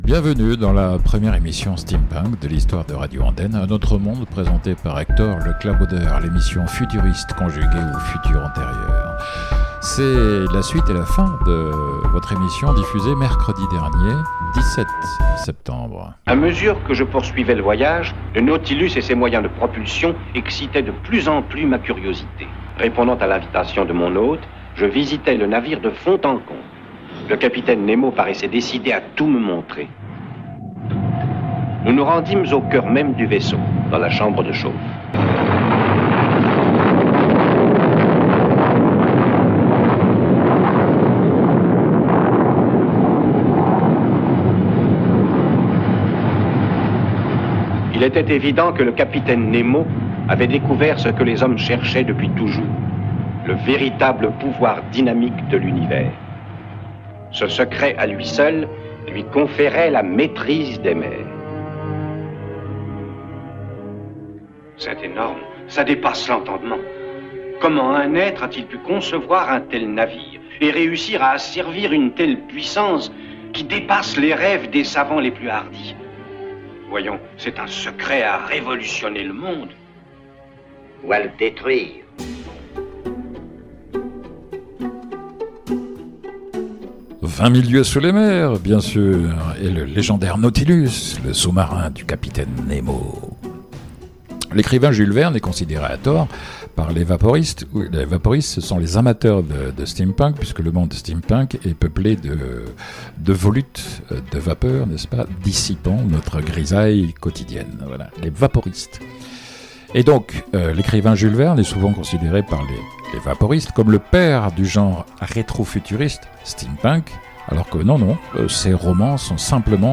(Steampunk)
(Electro-Swing)